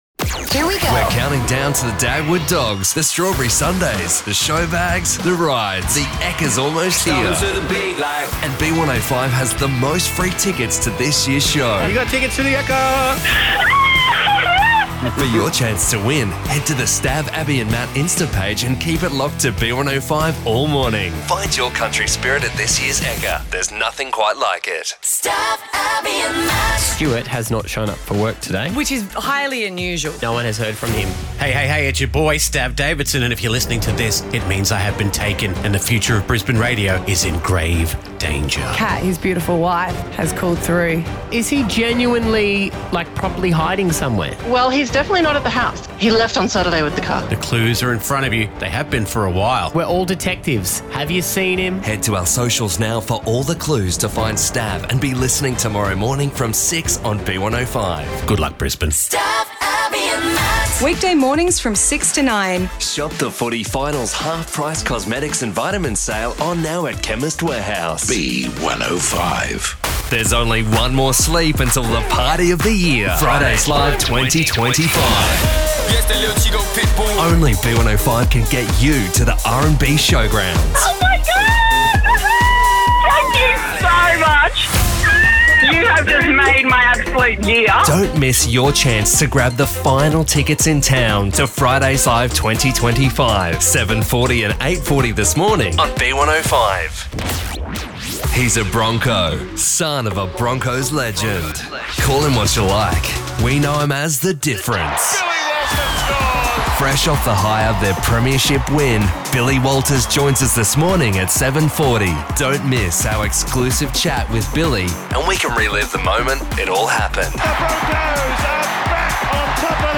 A friendly or corporate, warm or deep, upbeat or commercial, sales, conversational, educational, documentary, narrator voiceover. A radio and TV great voice for ads or spots.
An authentic, believable and conversational voice suitable for educational, corporate and commercial projects.